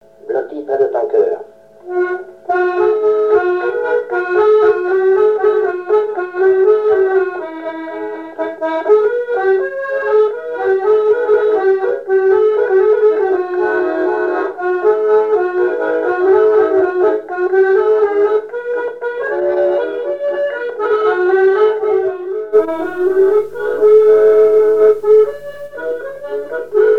Saint-Michel-Mont-Mercure
danse : valse
Pièce musicale inédite